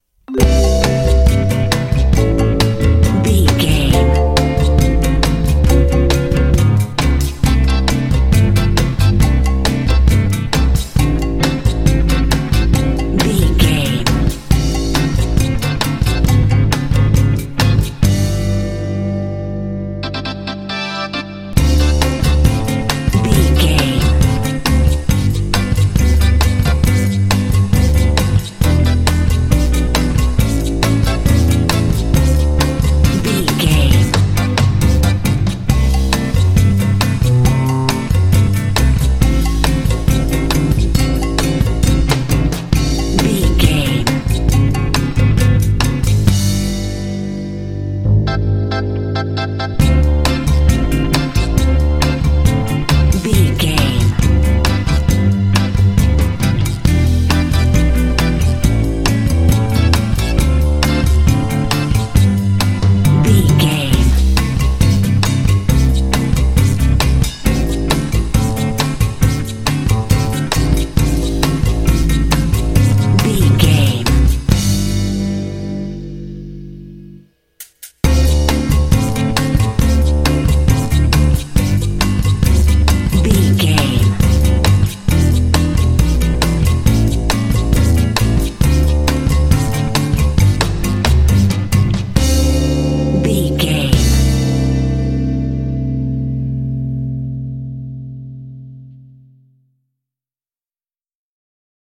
Ionian/Major
light
playful
uplifting
calm
cheerful/happy
electric guitar
bass guitar
percussion
electric organ